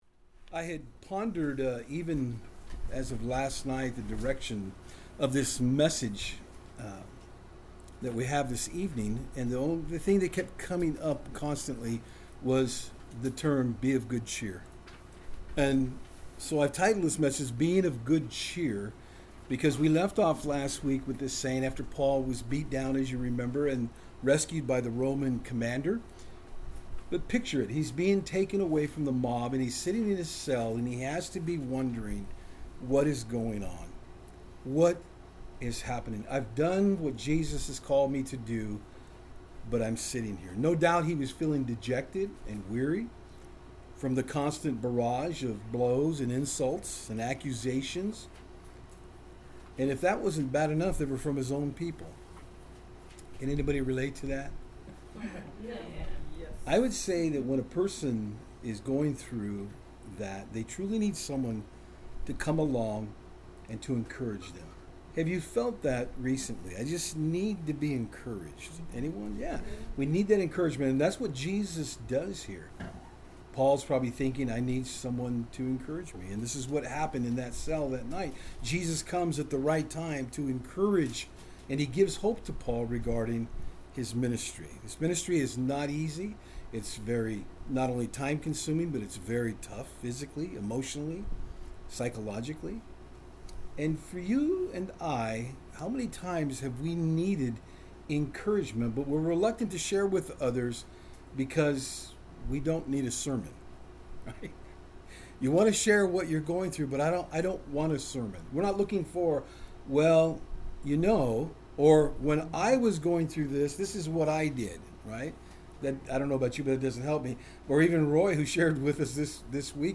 Acts 23:11-35 Service Type: Saturdays on Fort Hill Paul after nearly being bet to death was visited by Jesus in his cell.